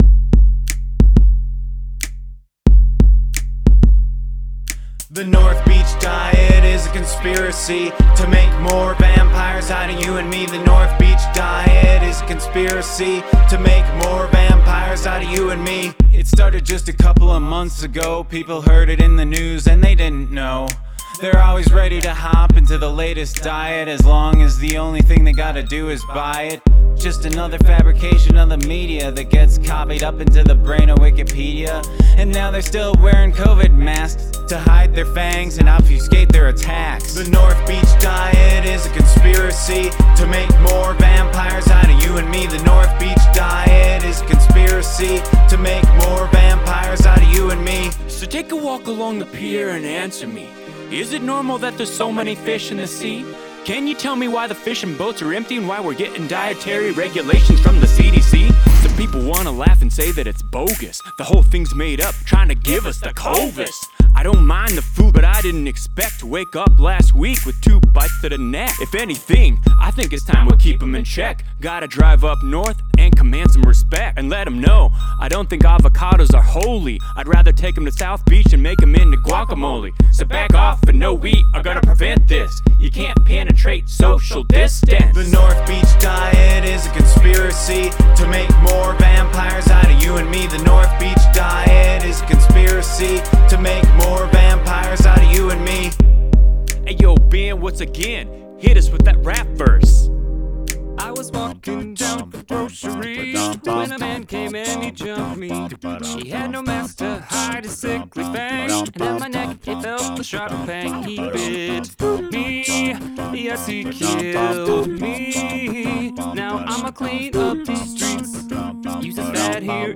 Include an a cappella section